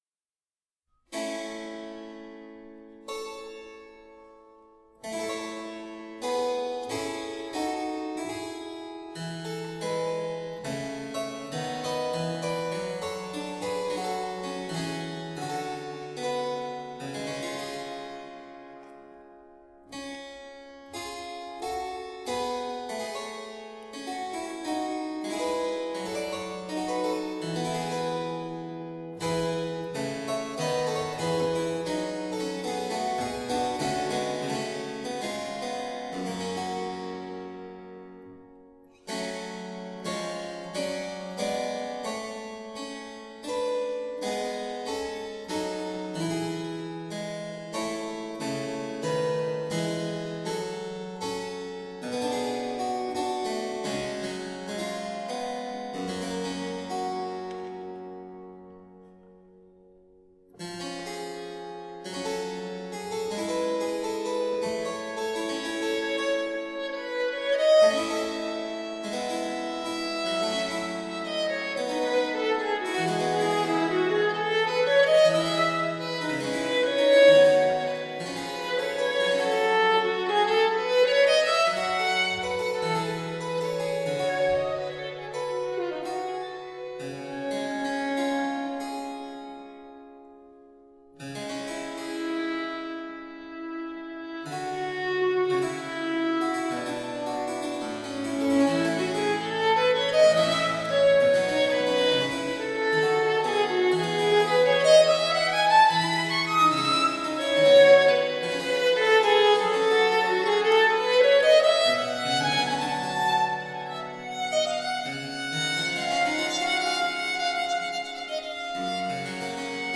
Small baroque chamber ensemble.
Tagged as: Classical, Chamber Music, Baroque, Instrumental